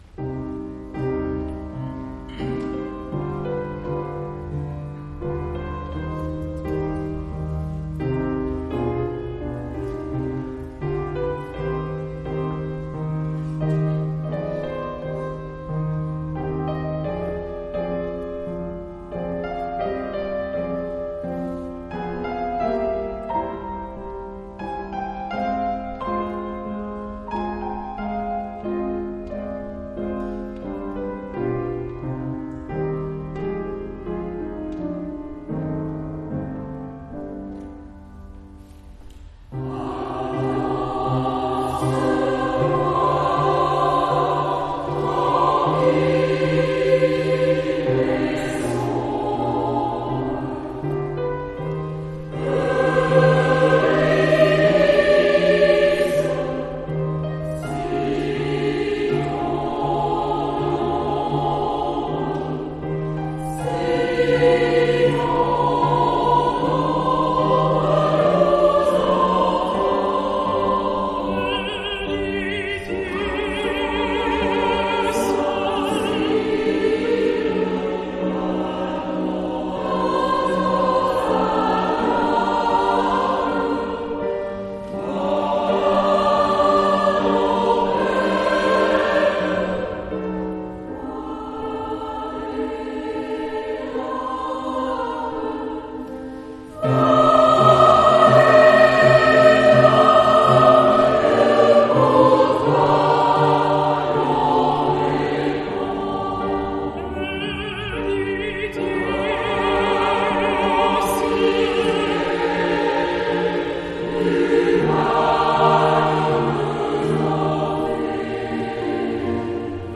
OPÉRA Orphée et Eurydice
Théâtre Sylvia Montfort
Choeur de la vallée de Montmorency